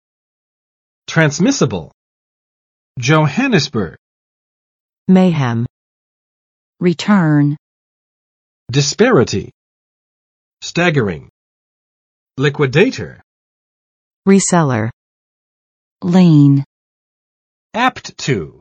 [trænsˋmɪsəb!] adj. 可传染的; 可传输的; 可传送的; 可传达的